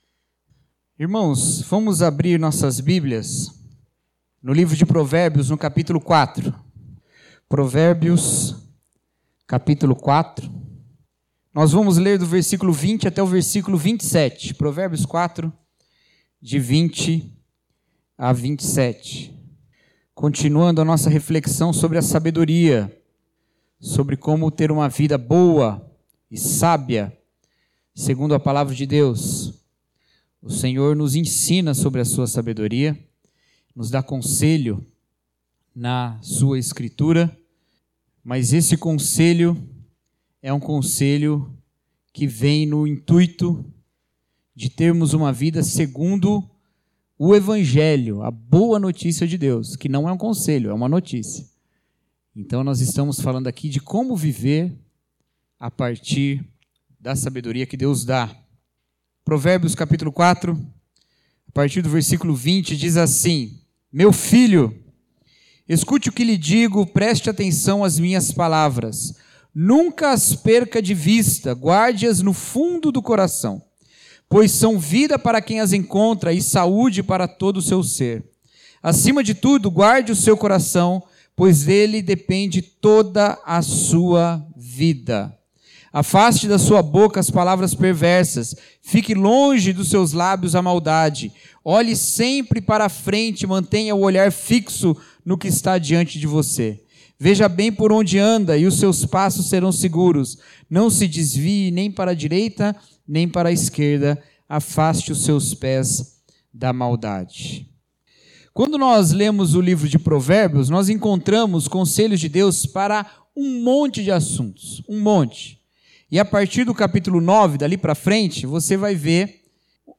Mensagem: A Integralidade da Sabedoria